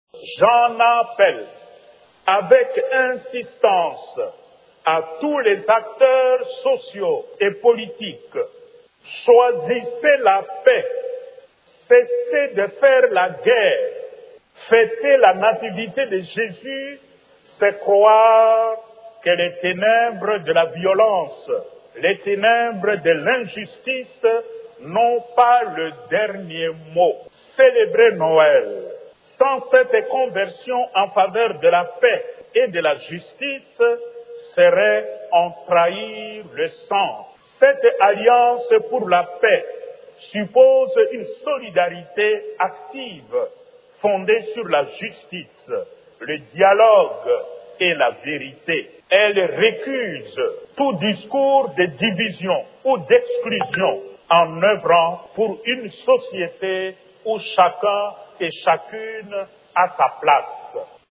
S’exprimant mercredi 24 décembre à Kinshasa lors de la messe de la nuit de Noël, le Cardinal Fridolin Ambongo a appelé les acteurs socio-économiques et politiques de la République démocratique du Congo à choisir la paix.